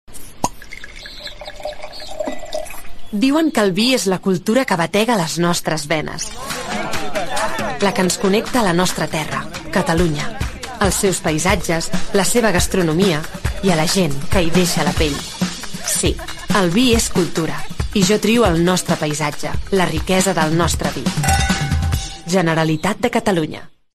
Falca ràdio català (mp3)